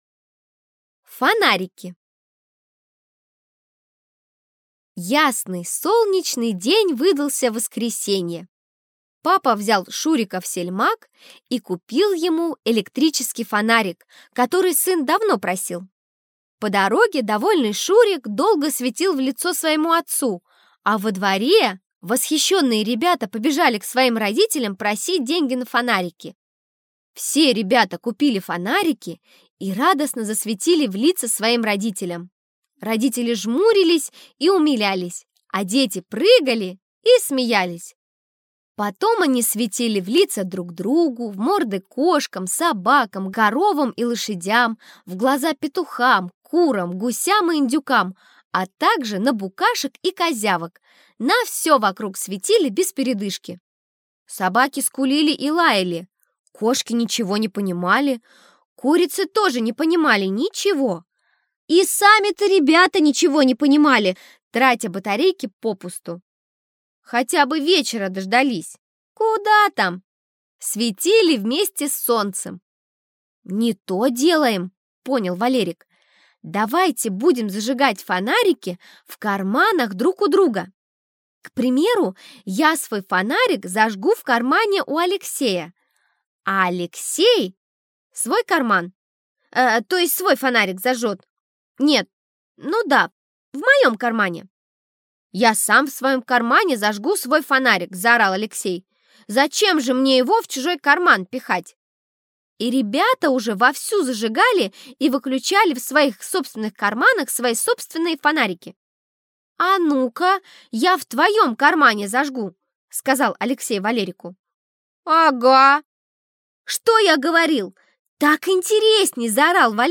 Аудиорассказ «Фонарики»